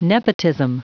Prononciation du mot nepotism en anglais (fichier audio)
Prononciation du mot : nepotism